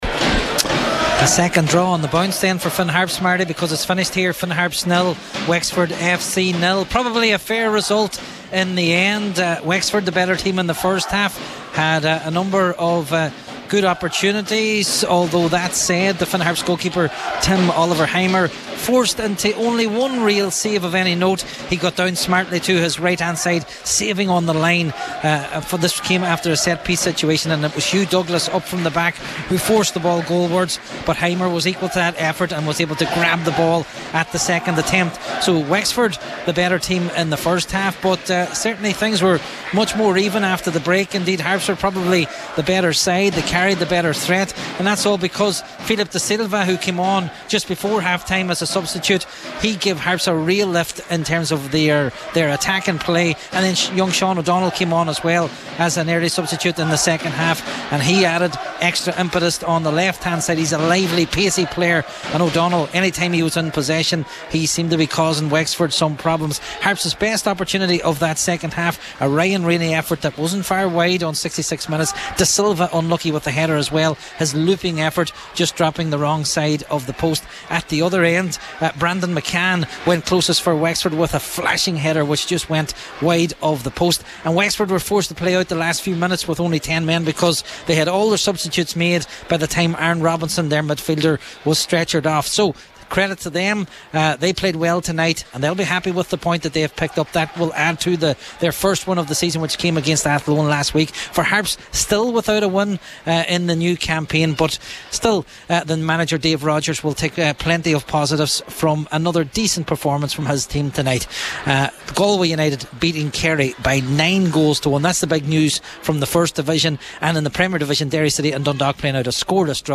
FT Report: Finn Harps 0 Wexford 0